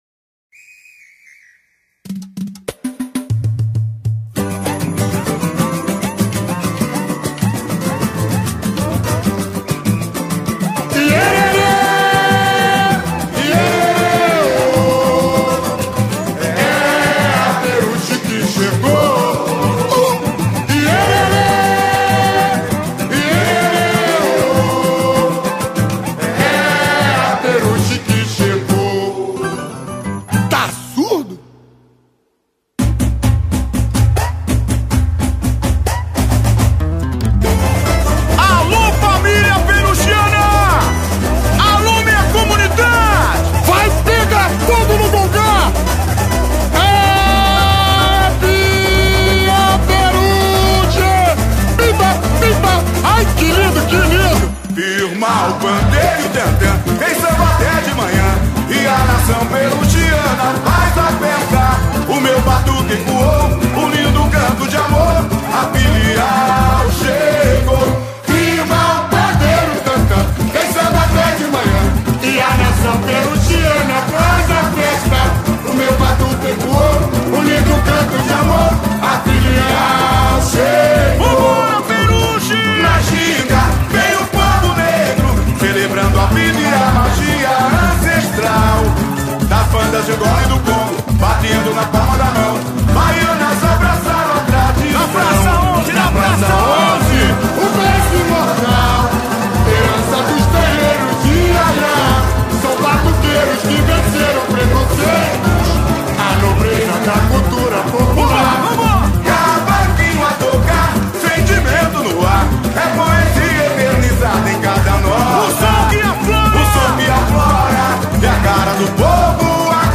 Puxador: